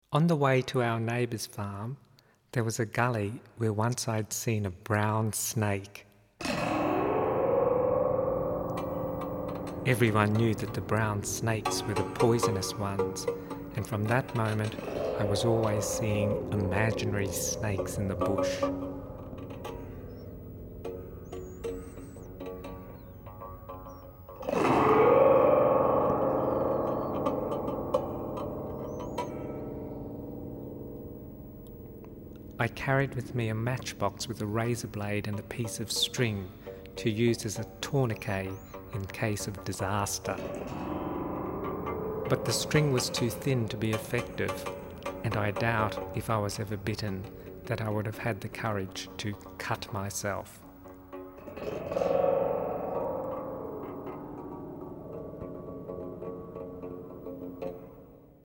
A lush musical painting of the Australian landscape.